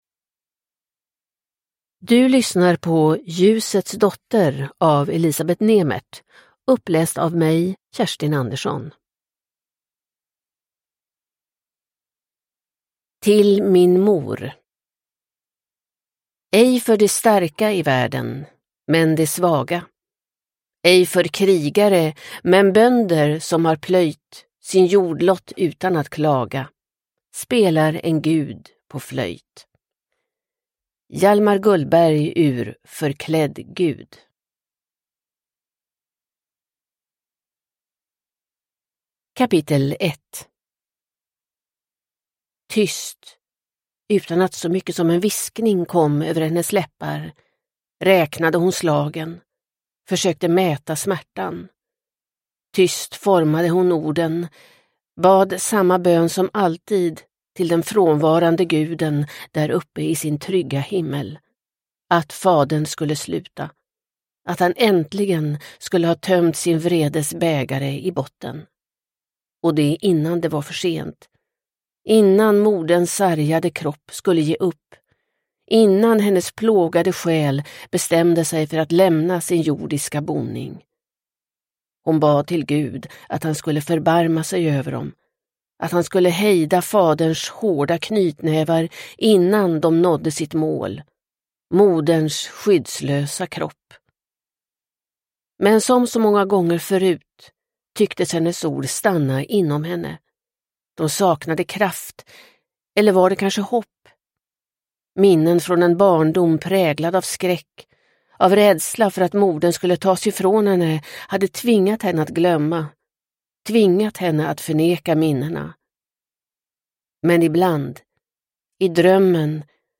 Ljusets dotter – Ljudbok – Laddas ner